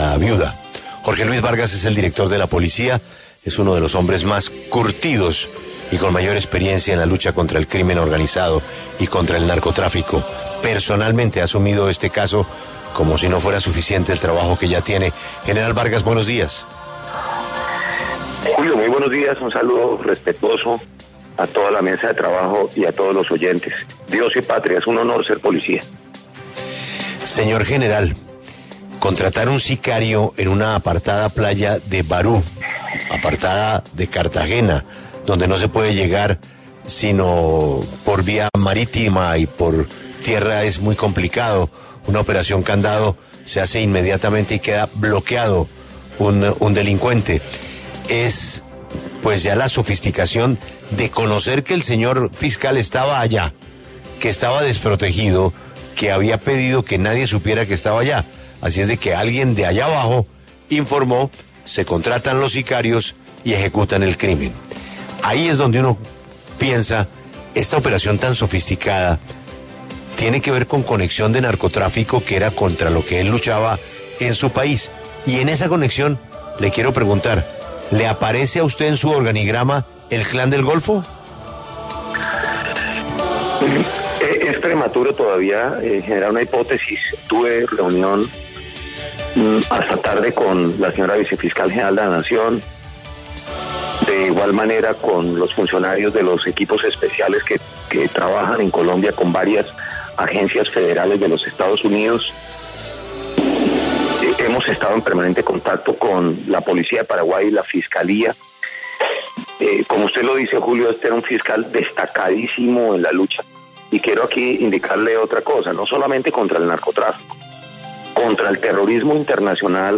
El general Jorge Luis Vargas se pronunció en La W sobre el asesinato de Marcelo Pecci, una de las personas más custodiadas de Paraguay.